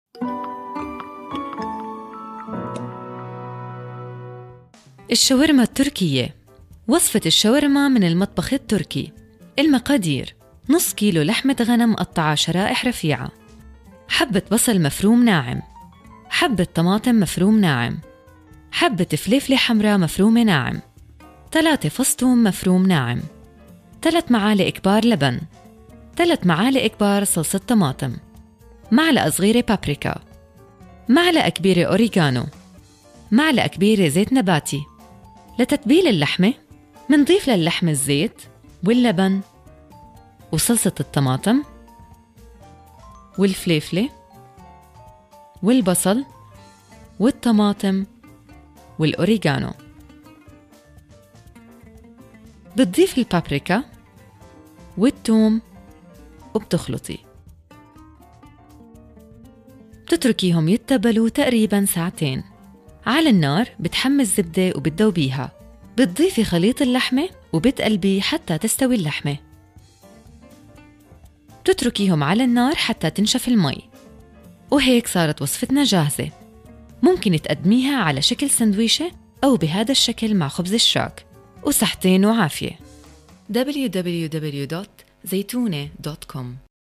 Professional, Warm, Fresh, Believable, clear, lively, Strong, Deep
Sprechprobe: Sonstiges (Muttersprache):